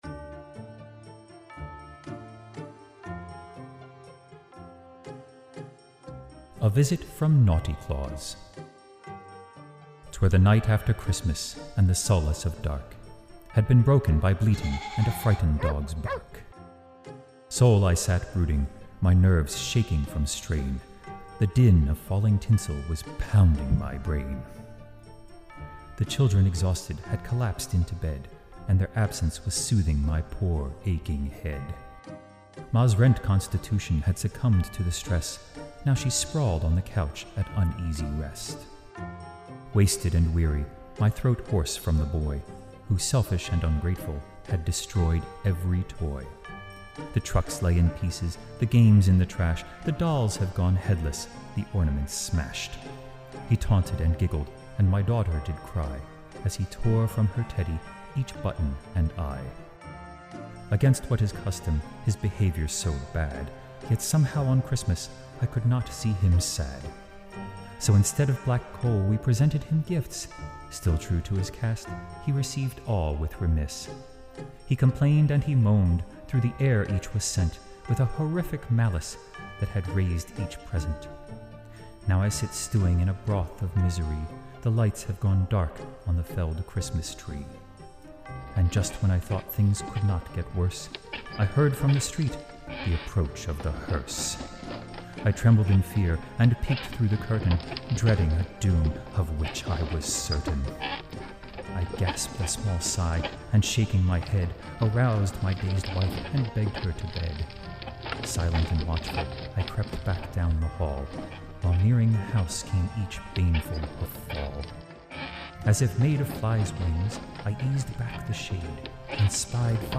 Dig into Jazz, click here and read for FREE Bad kids get coal, really bad kids get a Visit From Naughty Claus! A tale of holiday woe told in verse.
Naughty_Claus_Audio_SFX.mp3